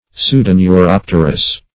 Search Result for " pseudoneuropterous" : The Collaborative International Dictionary of English v.0.48: Pseudoneuropterous \Pseu`do*neu*rop"ter*ous\, a. (Zool.) Of or pertaining to the Pseudoneuroptera.